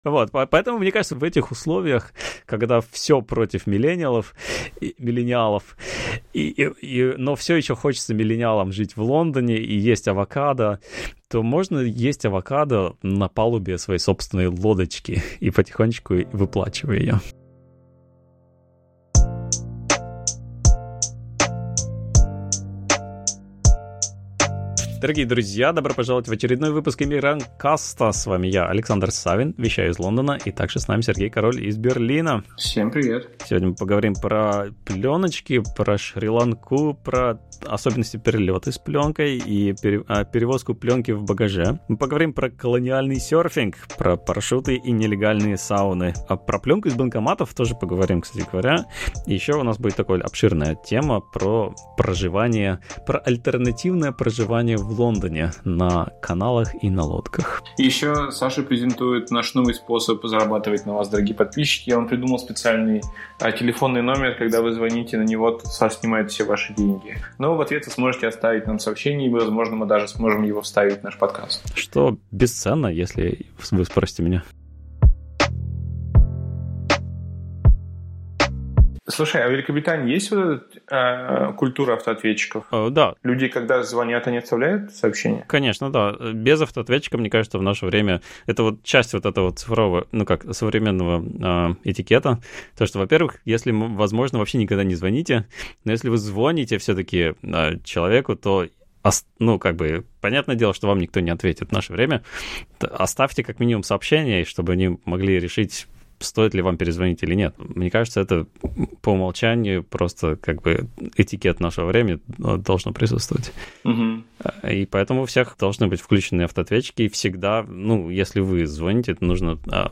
На проводе гости от Сингапура и Гоа до Франции и США — от дизайнера шрифтов до пилота самолета.